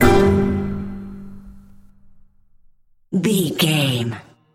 Uplifting
Diminished
flute
oboe
strings
orchestra
cello
double bass
percussion
goofy
comical
cheerful
perky
Light hearted
quirky